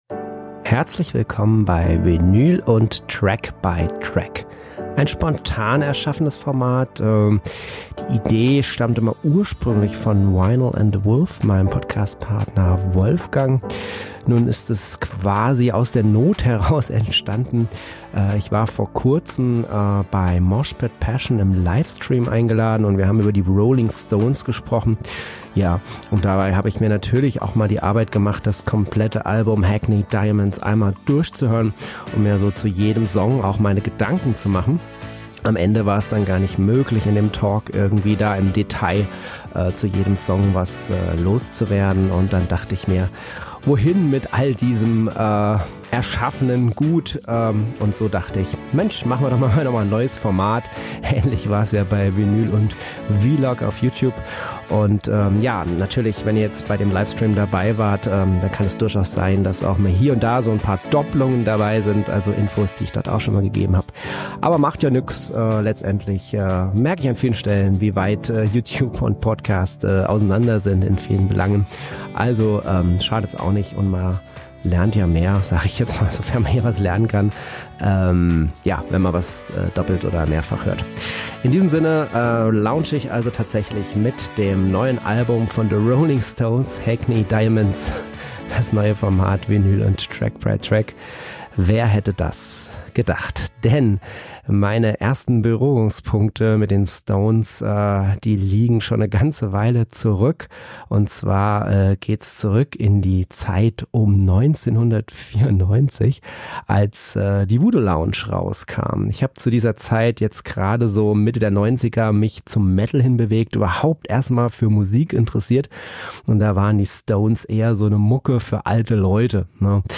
Hi-Fi-Talk zum Thema eurer Wahl findet statt am: Mo., 15.01.2023 ab 19.30 Uhr (Wahl des Themas in Kürze über Instagram, Facebook und Threads) In diesem Format spreche ich alleine oder mit Gästen über hoch relevante Alben, indem ich/wir sie Titel für Titel durchhören.